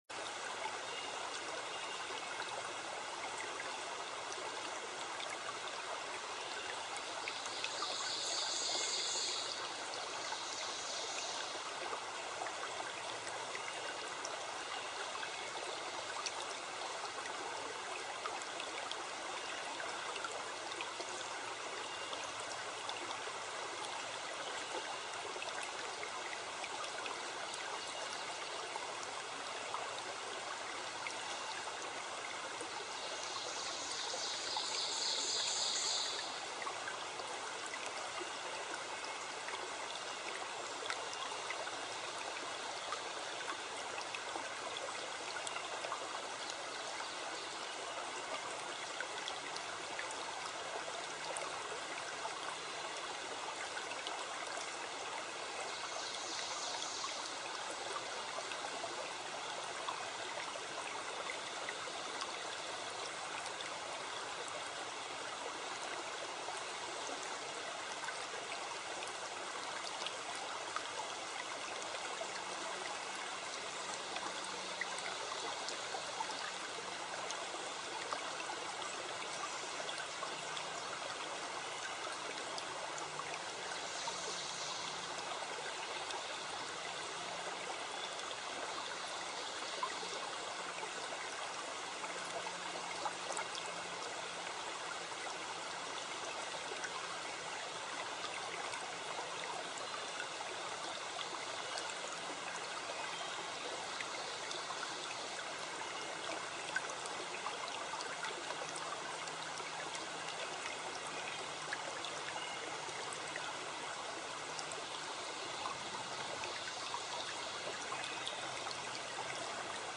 Trickling Stream and Birds to Calm You Down
Trickling-Stream-and-Birds-2.mp3